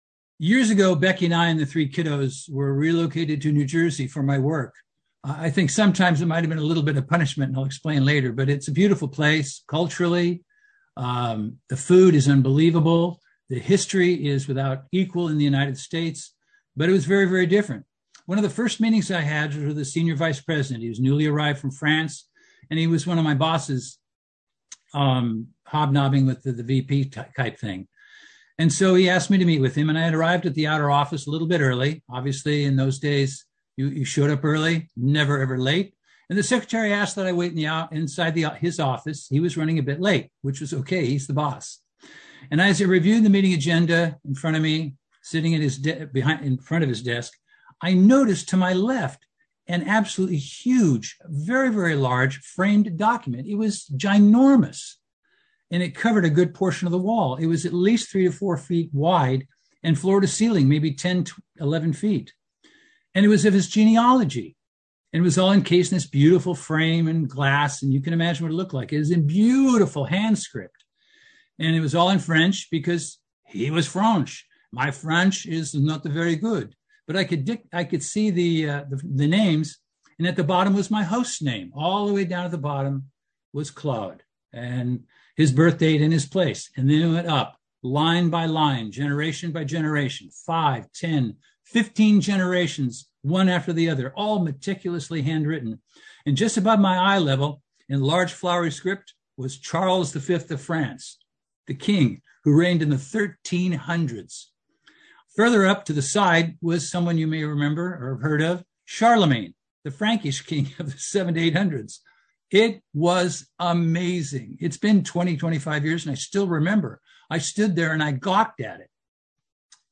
Sermons
Given in Los Angeles, CA Bakersfield, CA